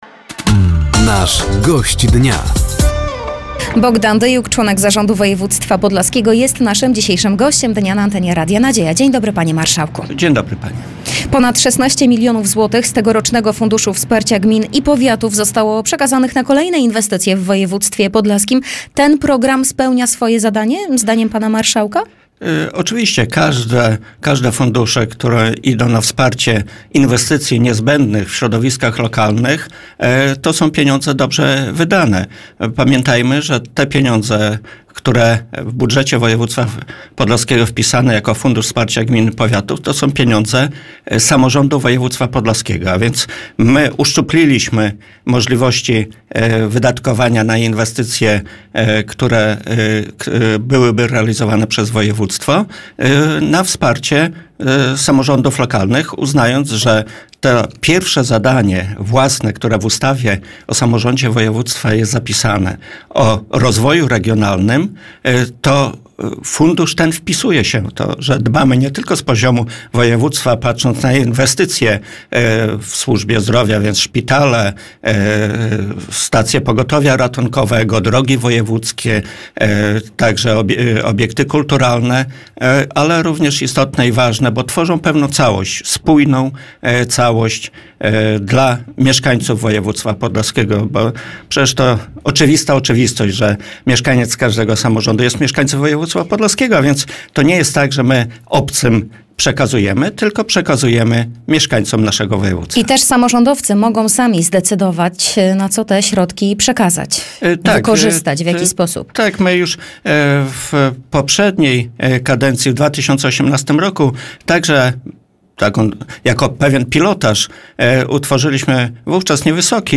O Funduszu Wsparcia Gmin i Powiatów, rozwoju oferty turystycznej województwa czy cennych obiektach takich jak Muzeum Błogosławionego ks. Jerzego Popiełuszki mówił wtorkowy (19.08) Gość Dnia Radia Nadzieja.